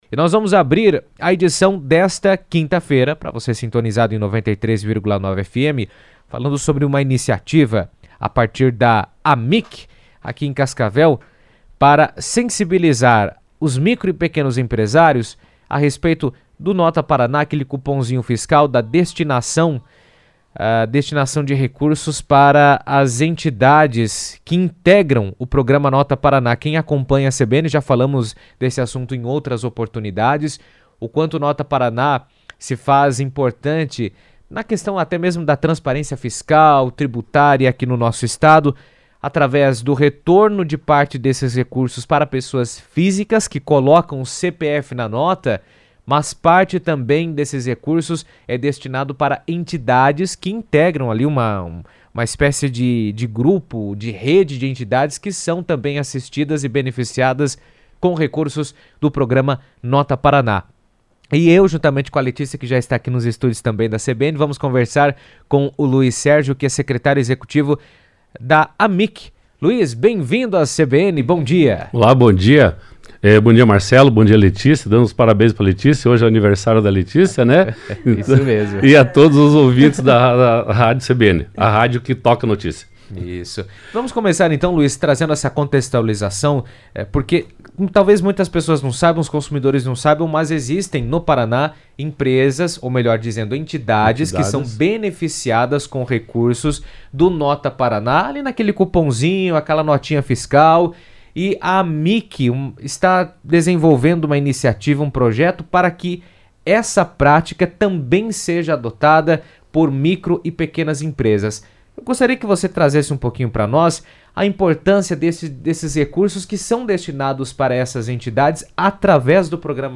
esteve na rádio CBN Cascavel falando sobre a campanha e destacando como gestos simples podem gerar grande impacto social.